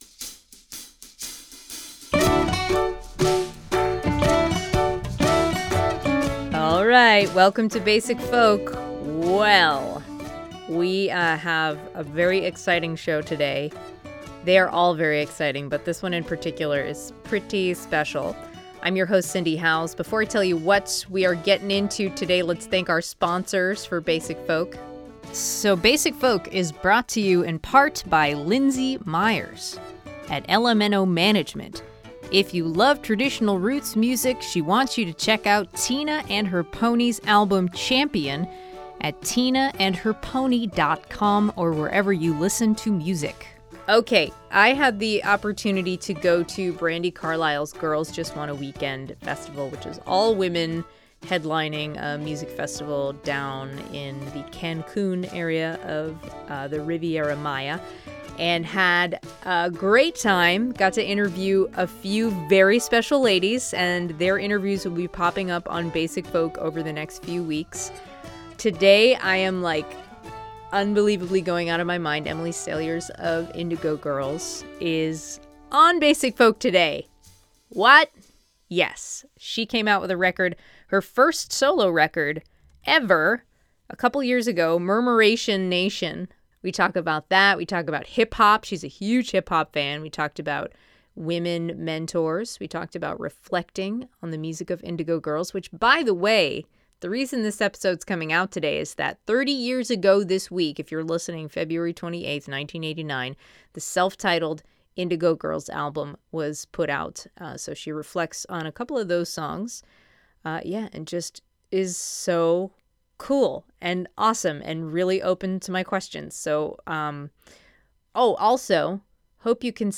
(recorded from webcast)